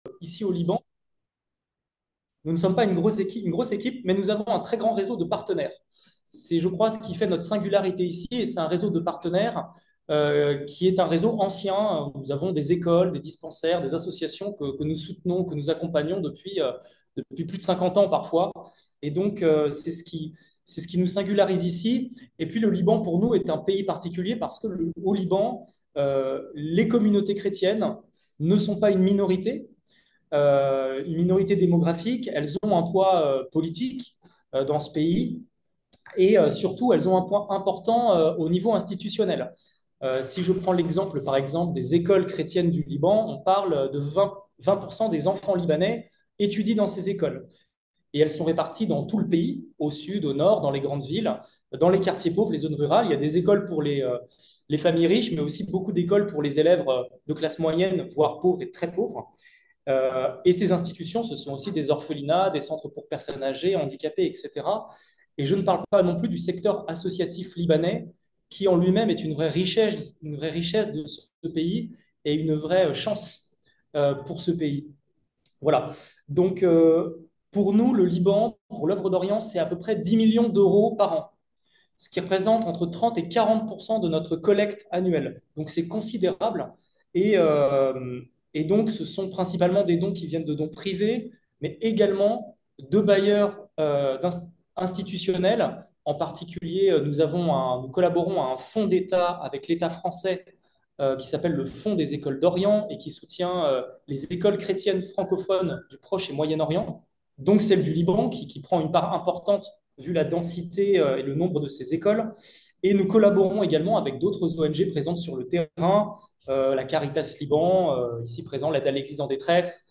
Guerre au Liban - Conférence de presse exceptionnelle du 8 octobre 2024 depuis Beyrouth - Soutenons de toute urgence la population libanaise